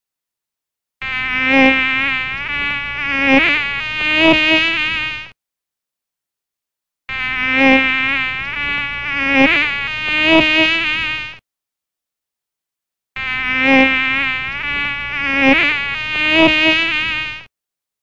FONDO MOSQUITO EFECTO DE SONIDO
Ambient sound effects
fondo_Mosquito___Efecto_de_Sonido.mp3